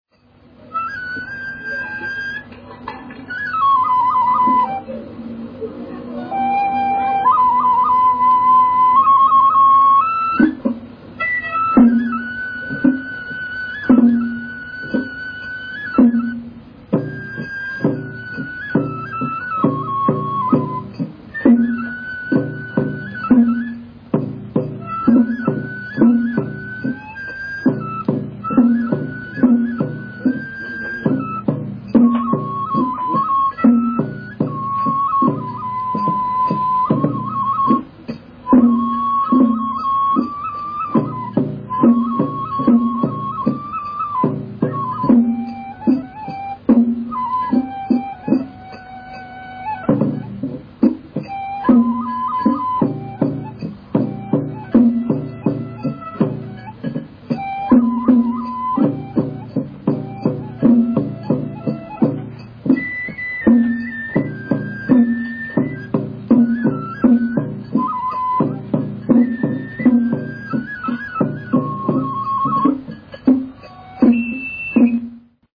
＜祭礼囃子＞
下の「試聴」バナーをクリックするとゆきわ会演奏の「祭礼囃子」が試聴できます。